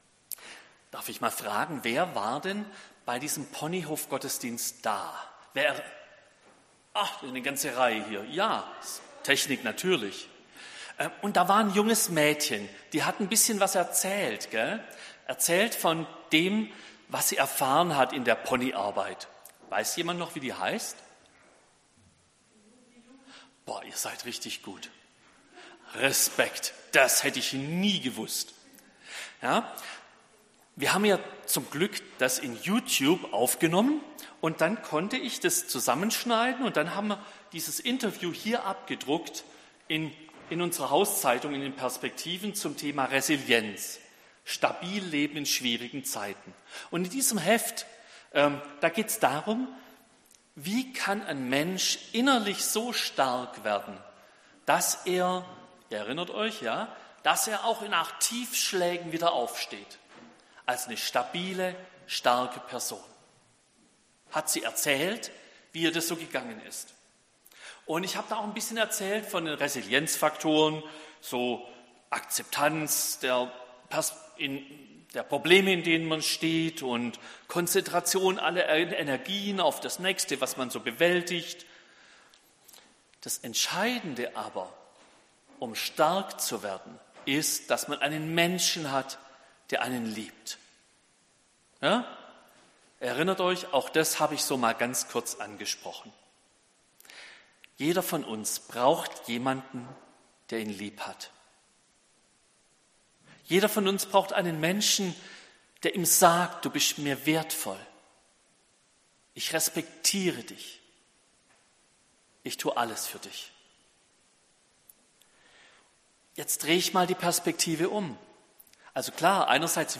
Predigt zu 1.